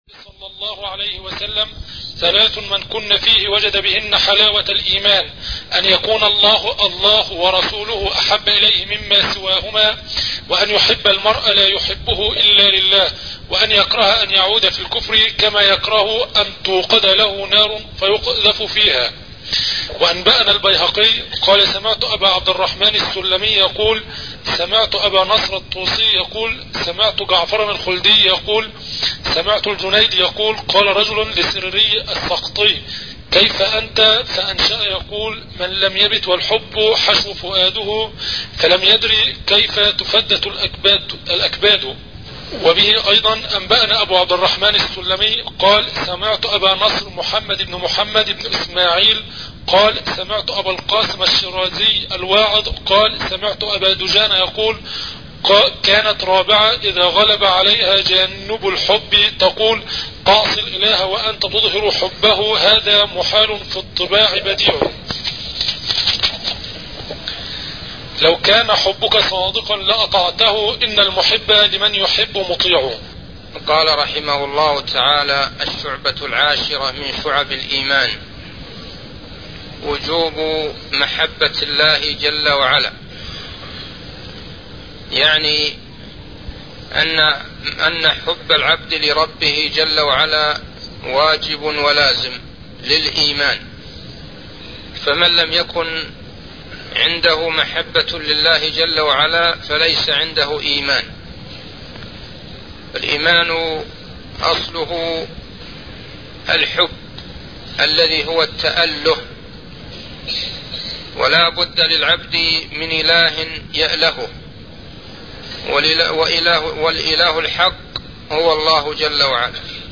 عنوان المادة الدرس (4) شرح مختصر شعب الإيمان تاريخ التحميل الأحد 29 يناير 2023 مـ حجم المادة 20.55 ميجا بايت عدد الزيارات 158 زيارة عدد مرات الحفظ 42 مرة إستماع المادة حفظ المادة اضف تعليقك أرسل لصديق